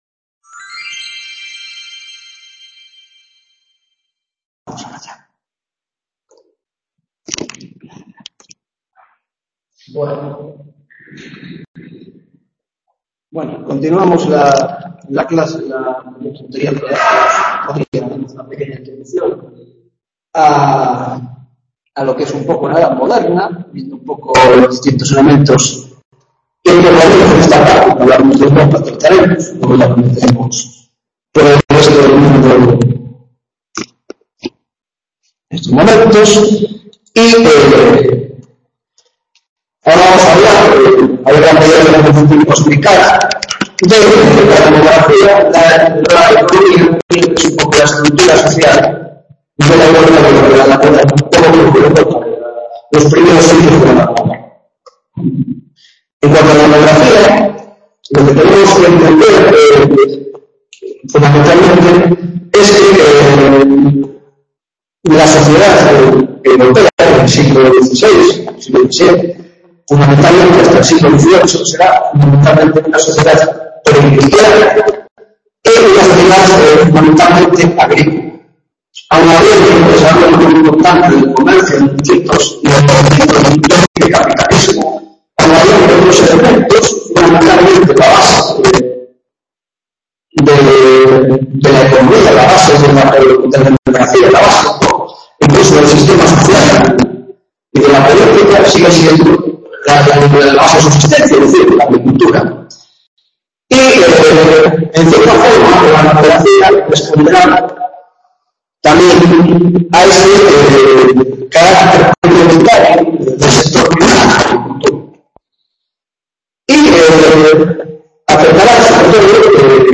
2ª tutoría de Historia Moderna, Grado de Antropología - Demografía Europa